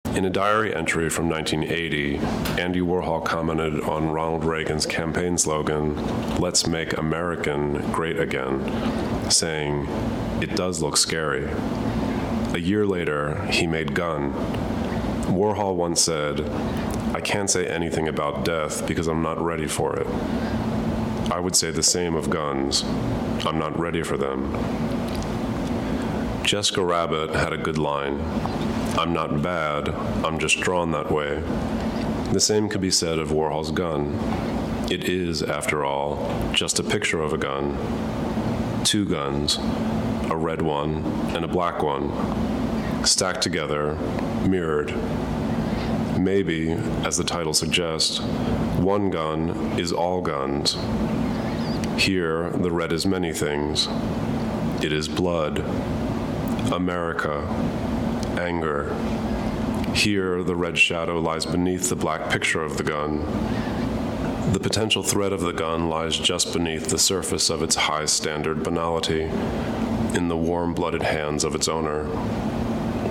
Clicking on it will let you listen to Da Corte himself as he comments on a work or on his own technique.
In addition to the title plaque, this was one of the works that had extensive comments by Da Corte, so I will include those comments so you can read them, and there will also be a "headphones" icon so you can listen to the comments as spoken by Da Corte himself.